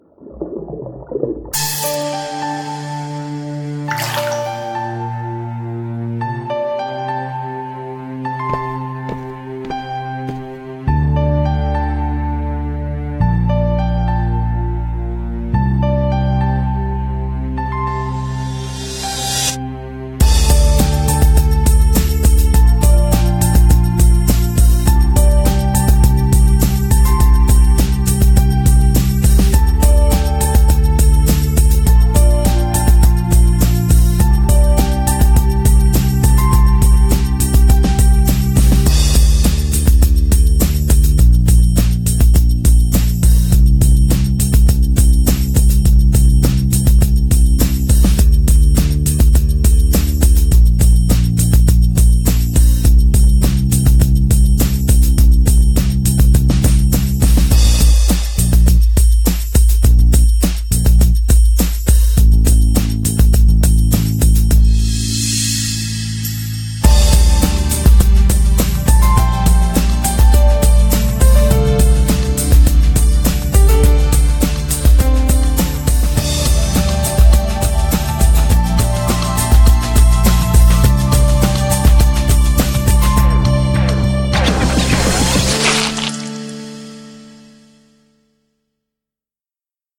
CM風声劇「ポミェとペッシェ